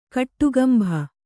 ♪ kaṭṭugambha